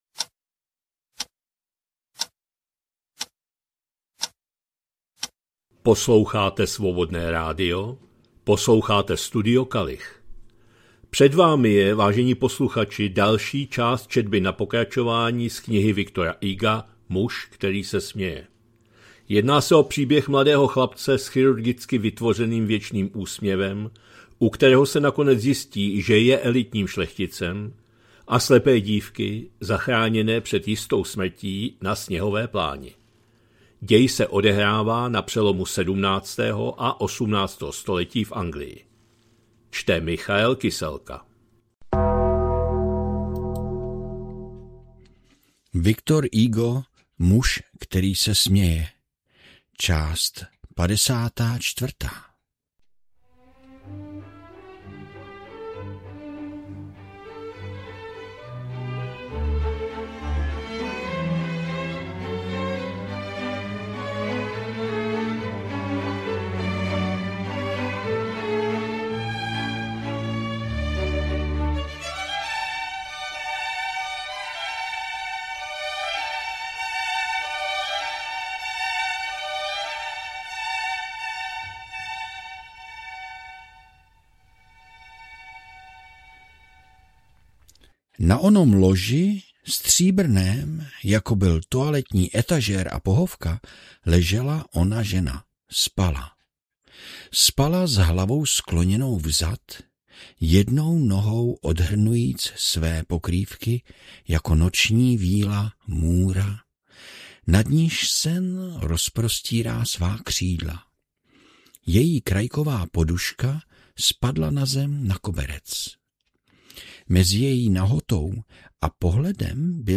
2026-03-26 – Studio Kalich – Muž který se směje, V. Hugo, část 54., četba na pokračování